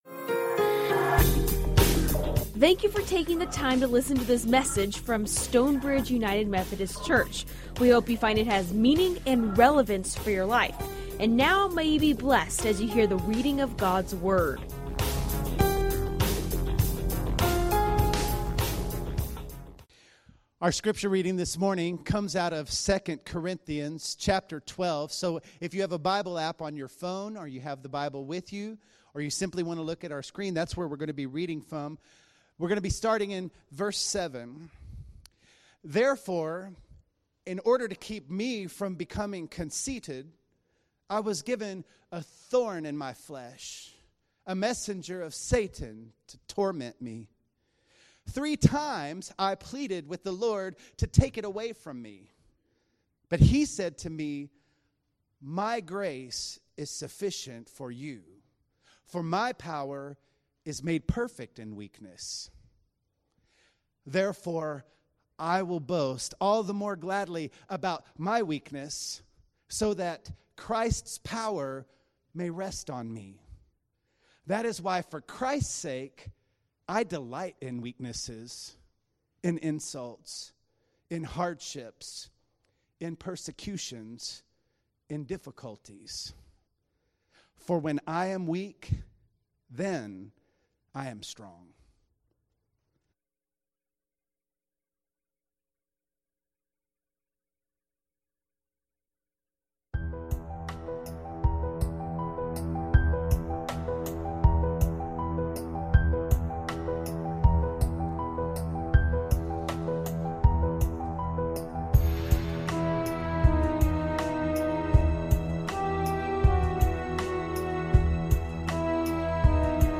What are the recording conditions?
Recorded live at Stonebridge United Methodiest Church in McKinney, TX.